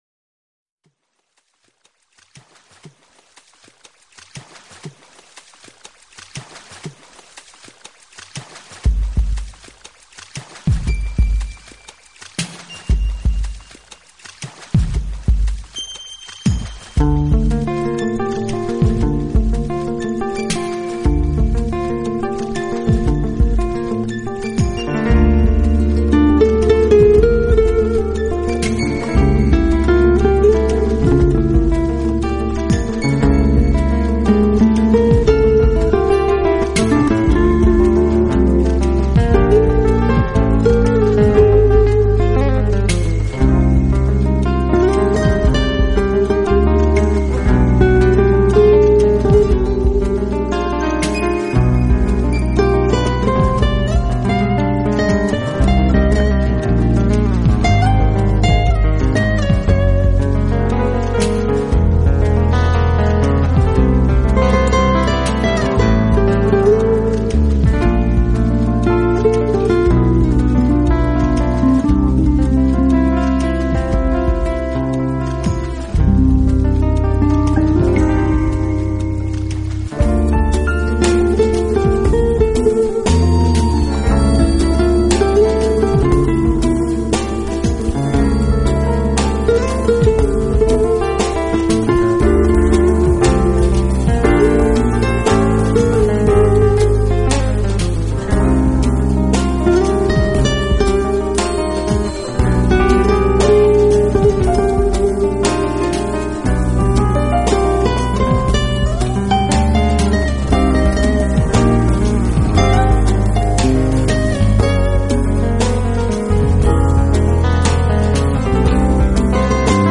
琵琶演奏，使他的音乐灵气大增。
guitars, percussion, marimba, harp, and finger cymbals
piano and keyboards
congas and percussion